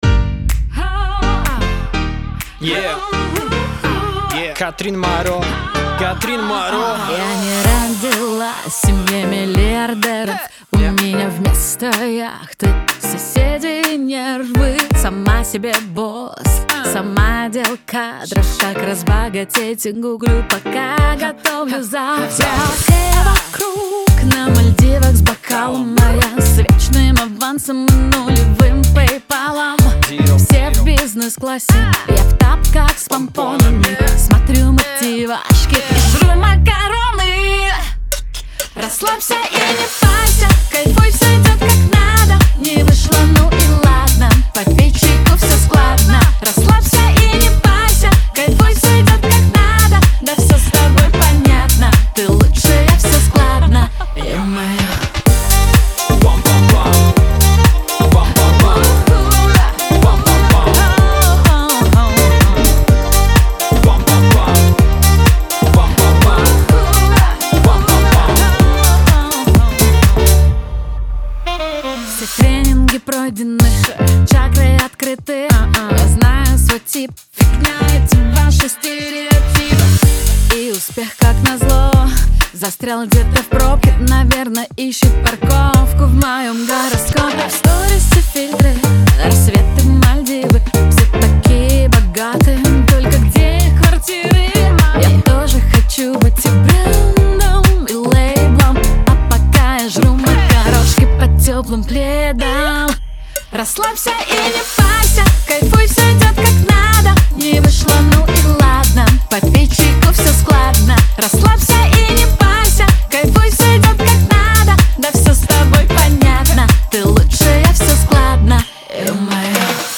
эстрада
pop , танцевальная музыка , диско